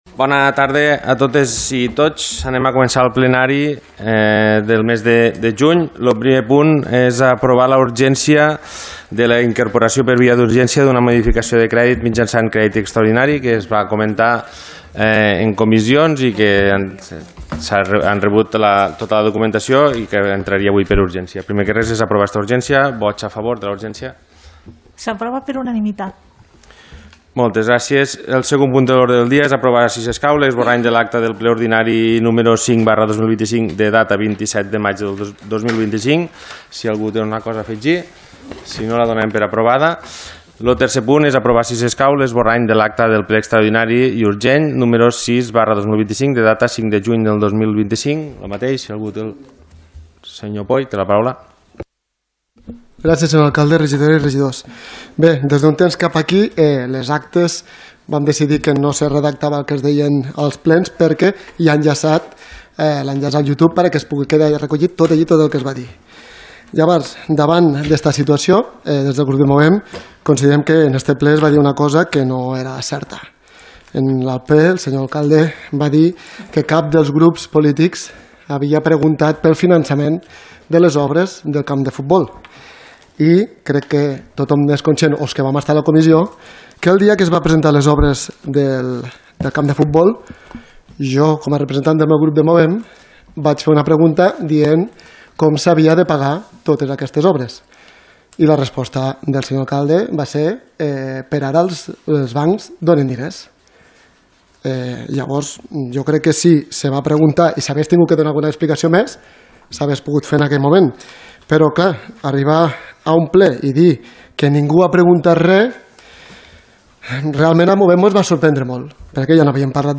Ple Ordinari de l’Ajuntament de Roquetes del mes de juny de 2025 | Antena Caro - Roquetes comunicació